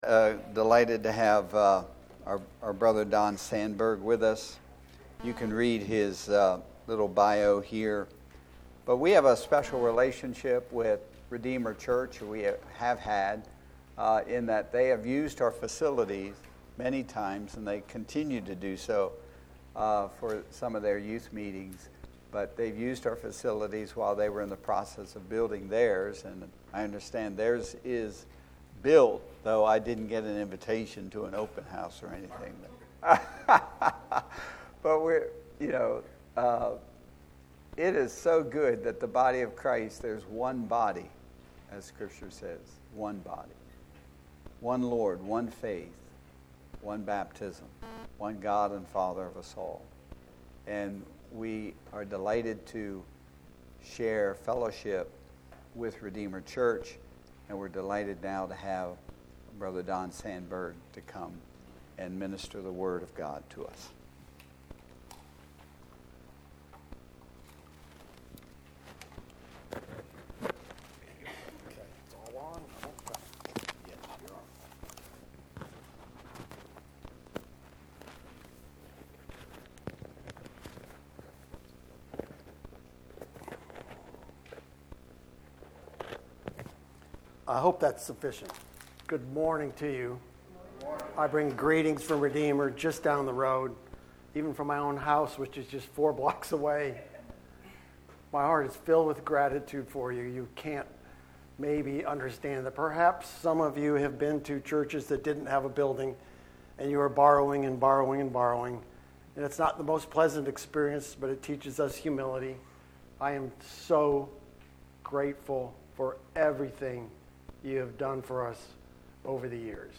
Anniversary Service